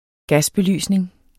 Udtale [ ˈgasbeˌlyˀsneŋ ]